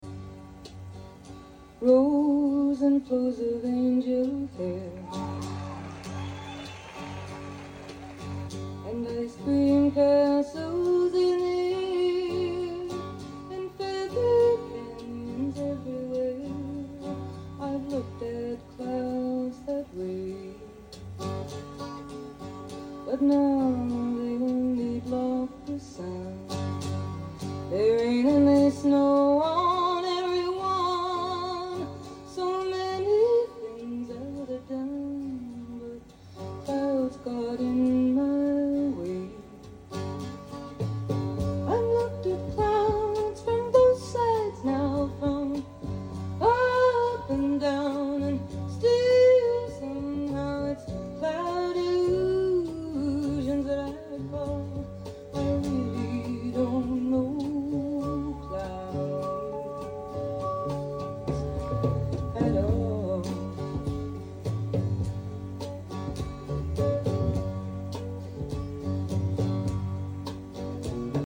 #70sjazz